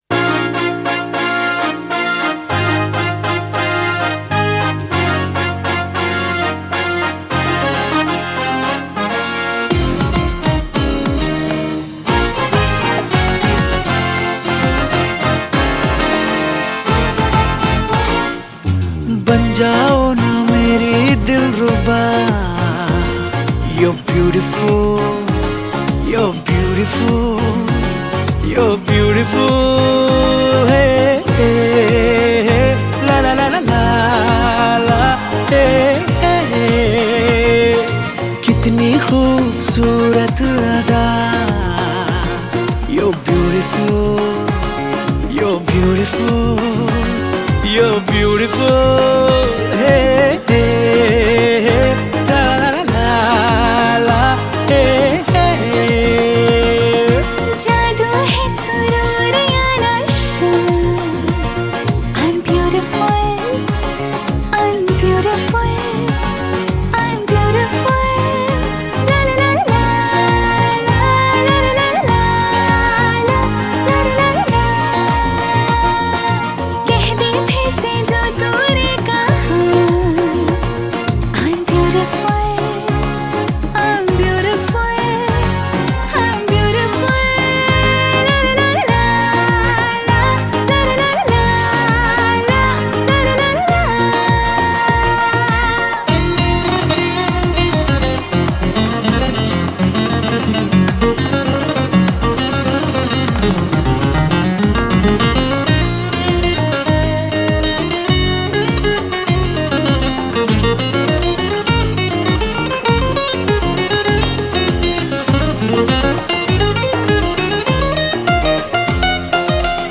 Category: Bollywood